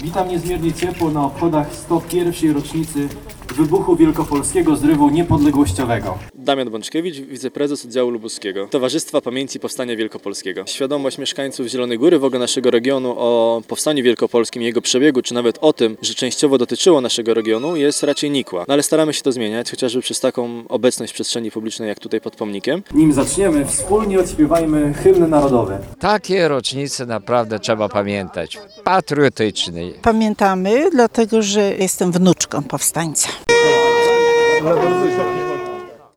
Na deptaku, przy pomniku Dobosza około 100 zielonogórzan upamiętniło dziś wybuch powstania wielkopolskiego. Mieszkańcy odśpiewali hymn, rotę na koniec zapalono znicze i złożono wiązanki przy tablicy upamiętniającej ten zwycięski zryw niepodległościowy.